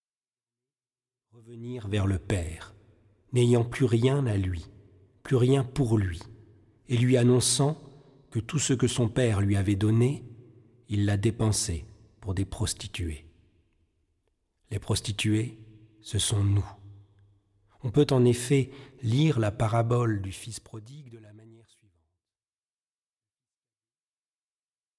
méditations spirituelles